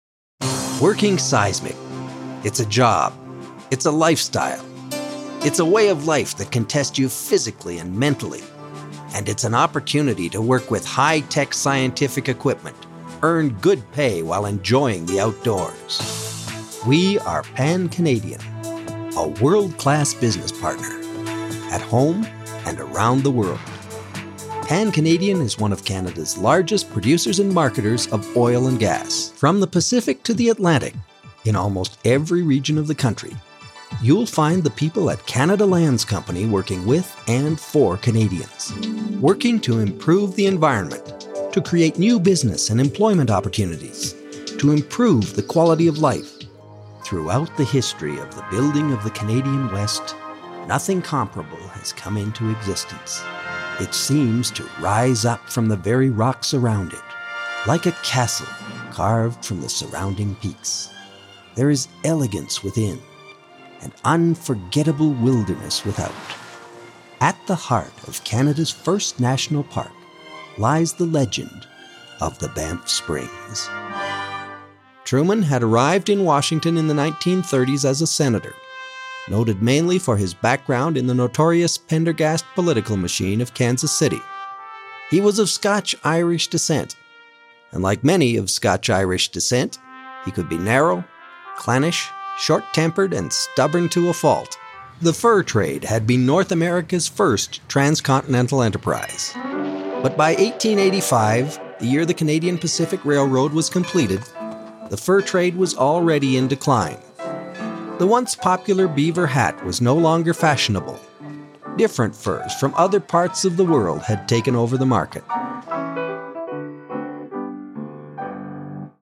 A signature voice - mature, warm, and engaging
Generic Narration Demo
Middle Aged